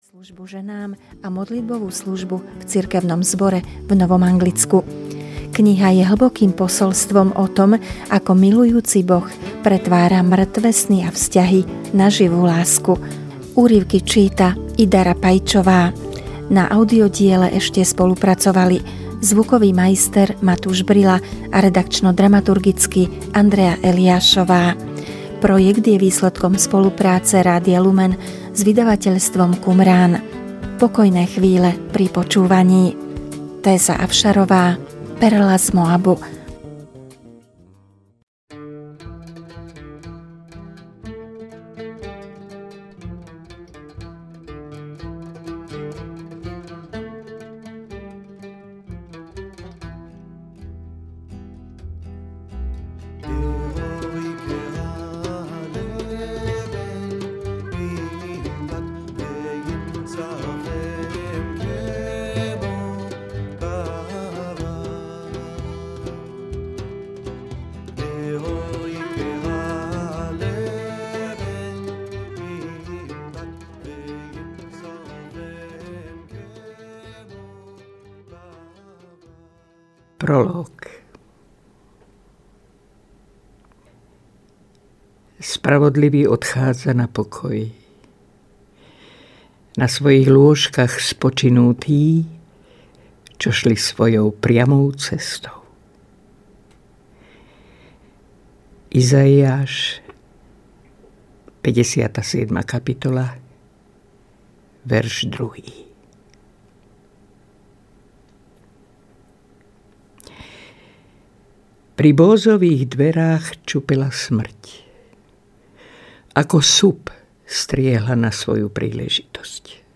Perla z Moabu audiokniha
Ukázka z knihy
• InterpretIda Rapaičová
perla-z-moabu-audiokniha